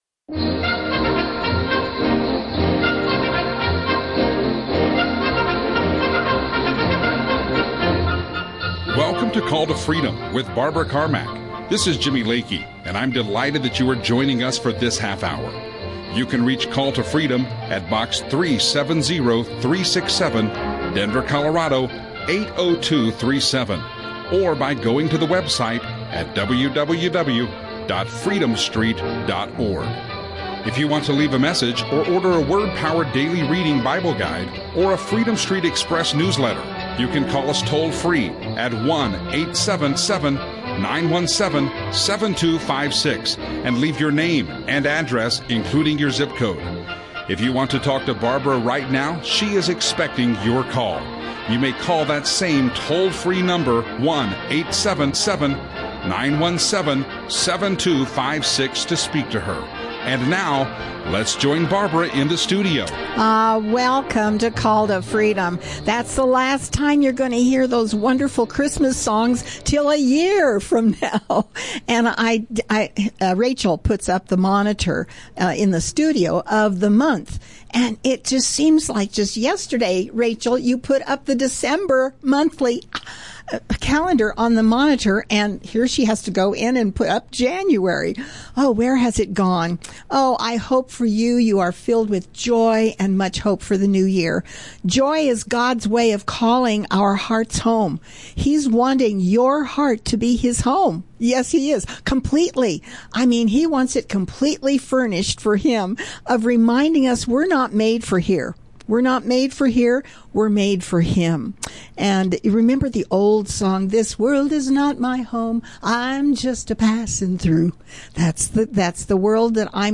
Audio teachings